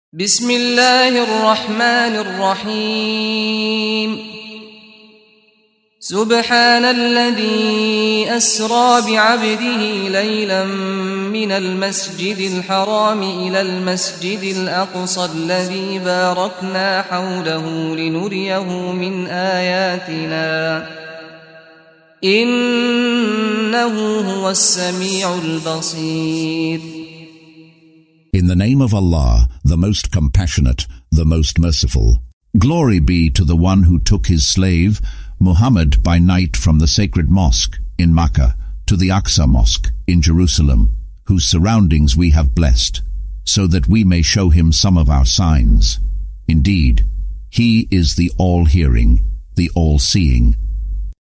Audio version of Surah Al-Isra ( The Night Journey ) in English, split into verses, preceded by the recitation of the reciter: Saad Al-Ghamdi.